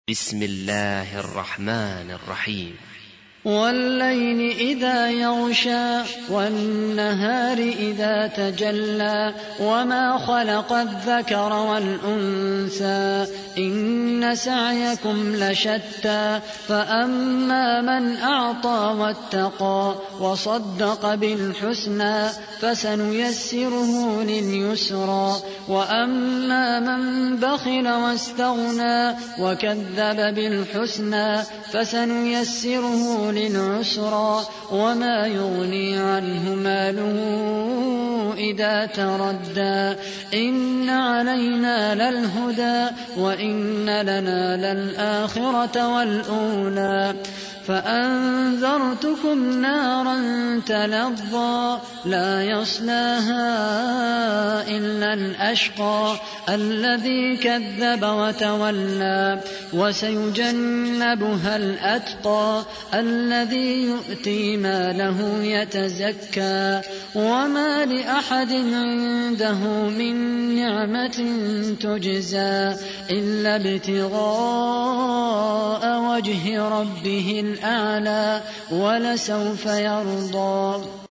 92. سورة الليل / القارئ